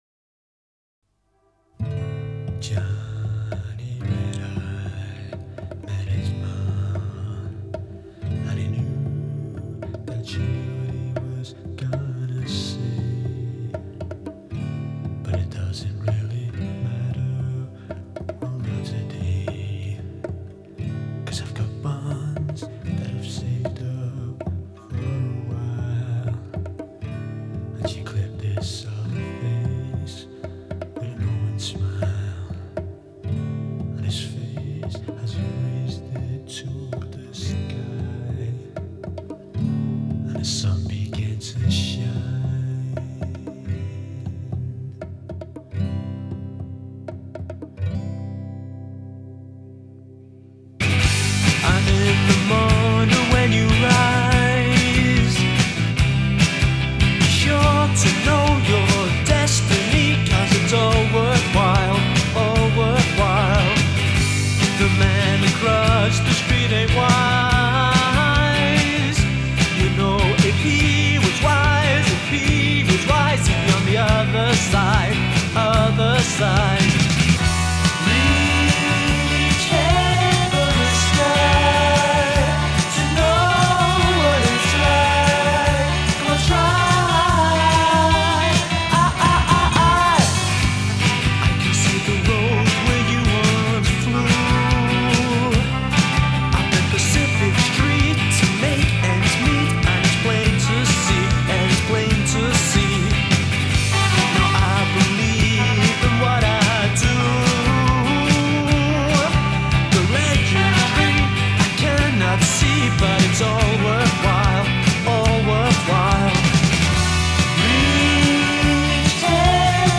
English post-punk band
Genre: Indie, Lo-Fi, New Wave